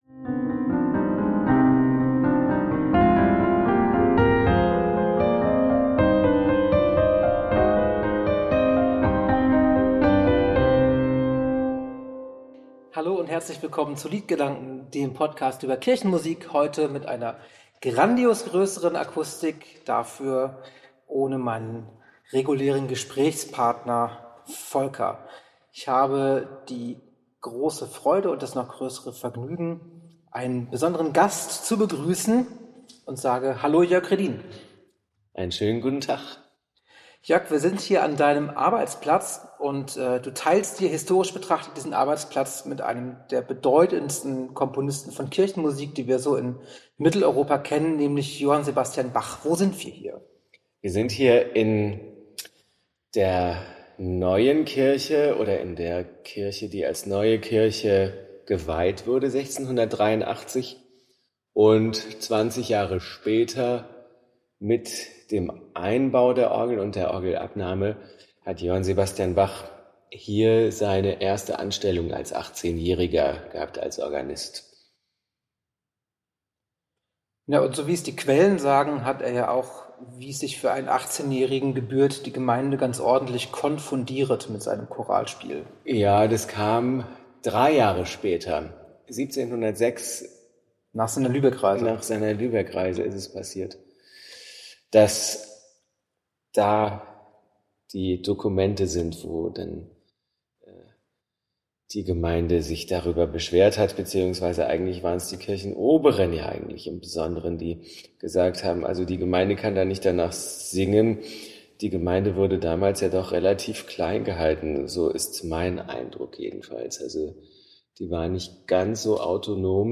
In der Arnstädter Bachkirche